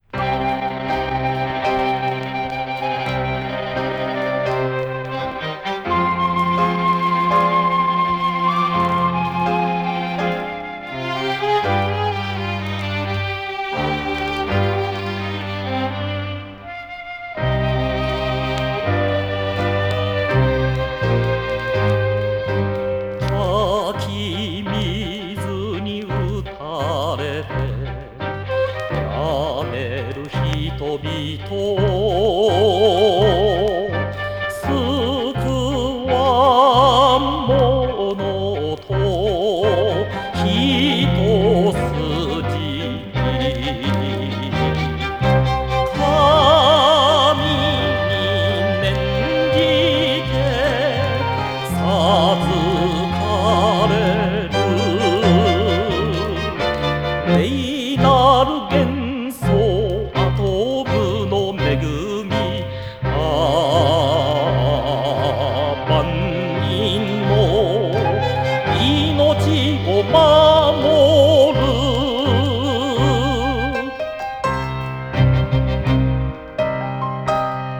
コメント：｢今回のテープはビデオのサントラ集です｡最初にはいっているのはだいぶ前に友人がゴミ捨場から拾ってきたソノシートから録りました｡あれも良いこれも良いてな感じで入れたのでゴチャゴチャしてますが、適当な部分を使ってやってください｡」